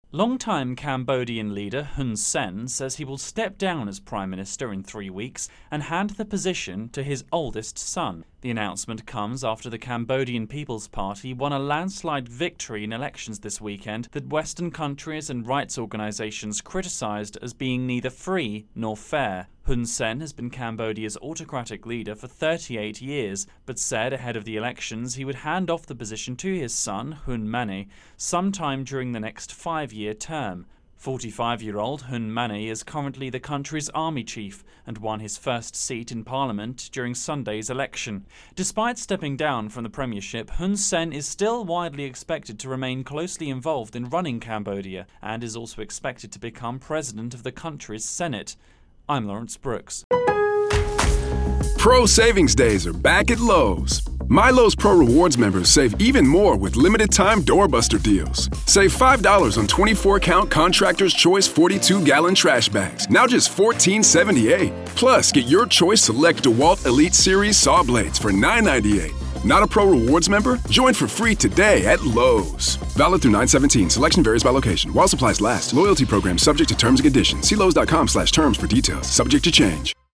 reports on Cambodian politics